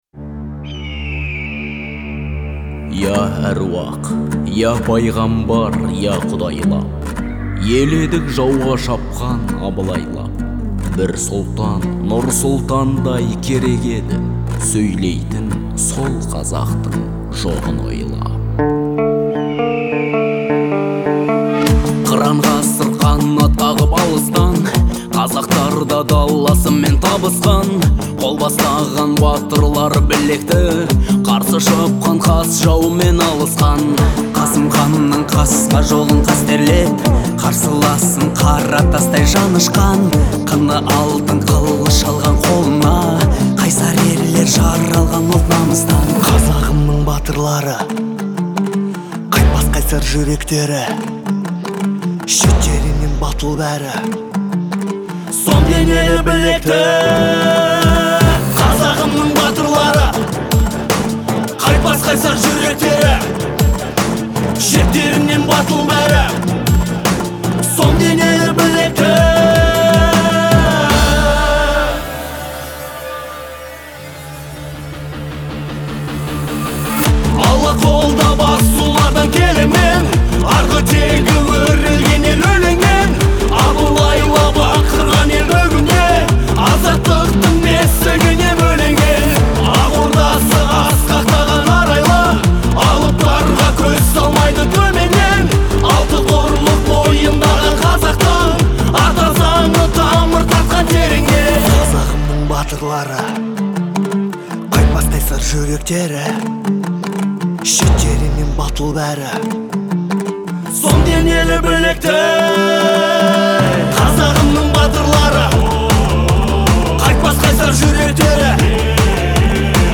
это мощное произведение в жанре патриотической музыки